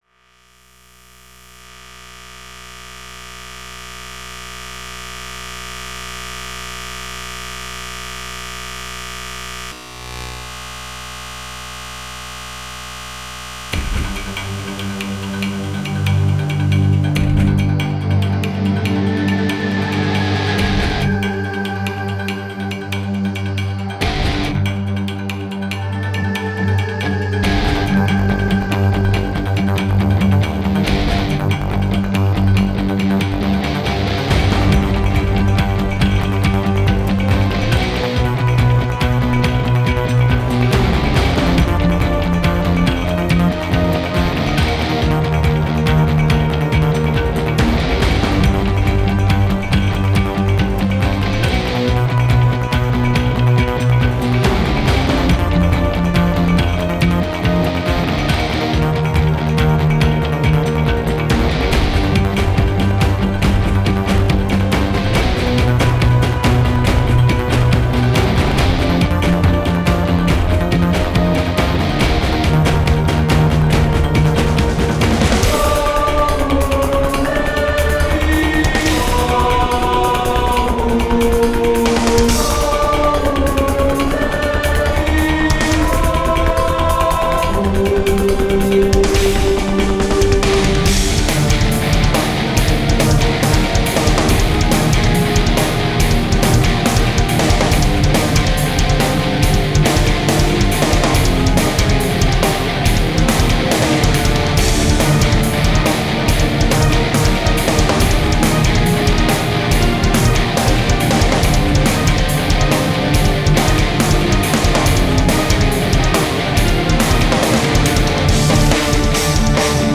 Style : Orchestral metal
cinematic epic battle / pursuit